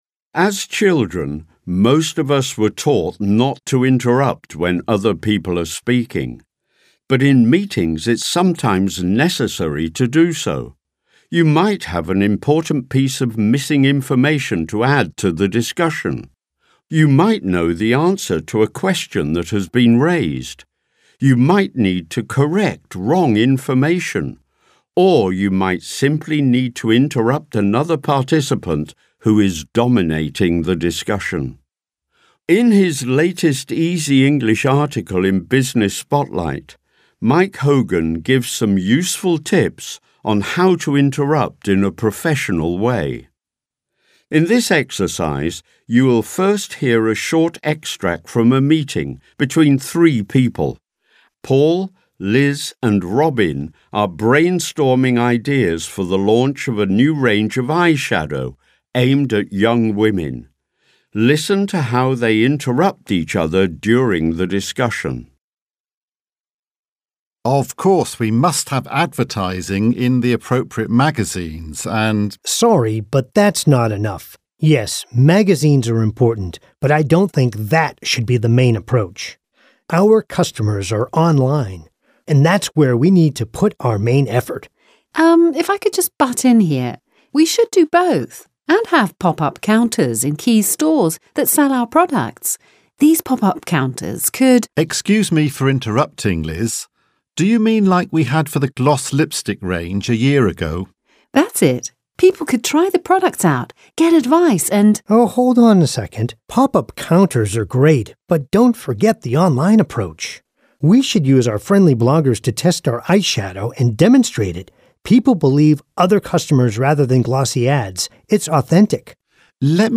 Easy English - Exercise: Dialogue | ZSD Content Backend
Audio-Übung